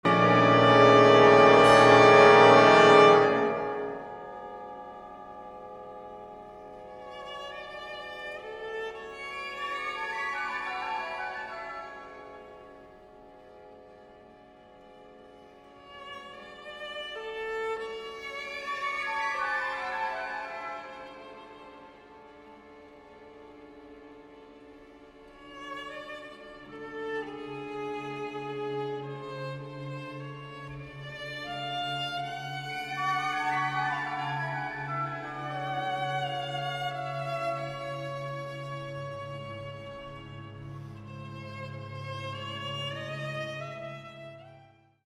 Largo – (7:48)